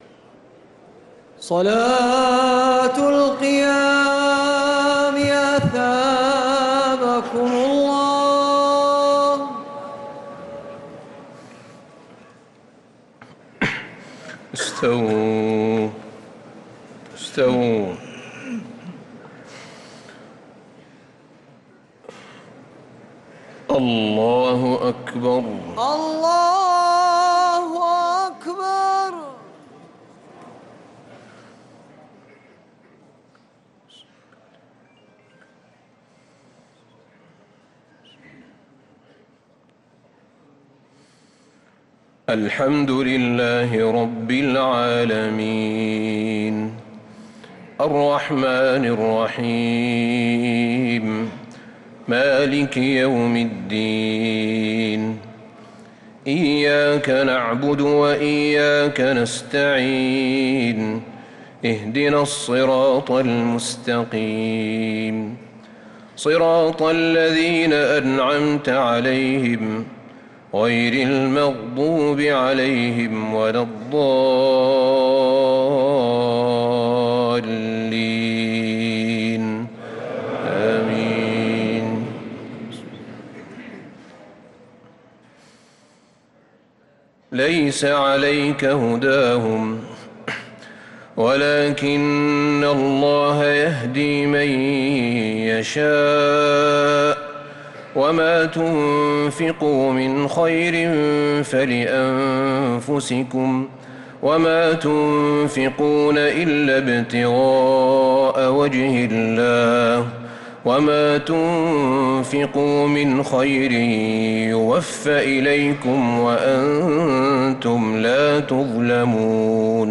تراويح ليلة 4 رمضان 1446هـ من سورتي البقرة {272 -286} و آل عمران {1-41} Taraweeh 4th night Ramadan 1446H Surah Al-Baqara Surah Aal-i-Imraan > تراويح الحرم النبوي عام 1446 🕌 > التراويح - تلاوات الحرمين